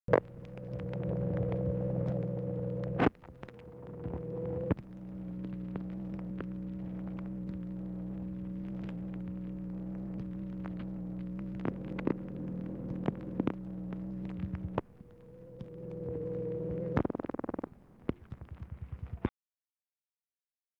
Conversation with BOURKE HICKENLOOPER, December 12, 1963
Secret White House Tapes